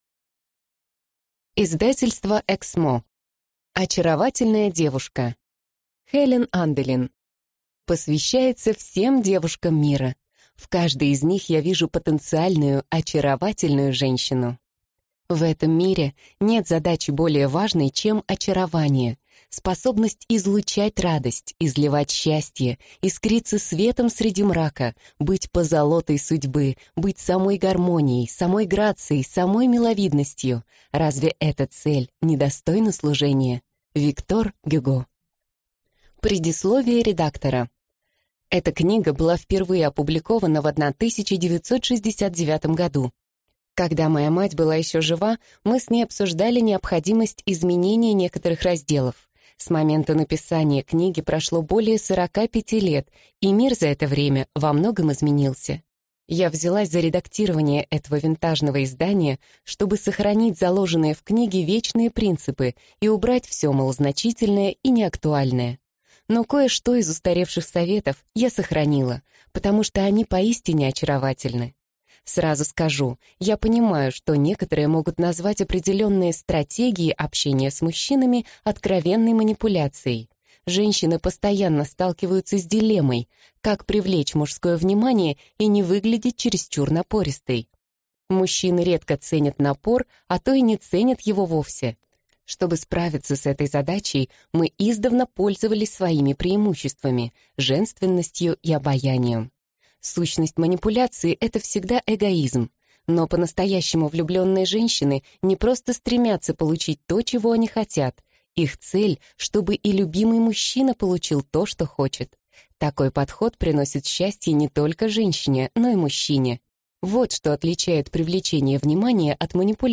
Аудиокнига Очаровательная девушка | Библиотека аудиокниг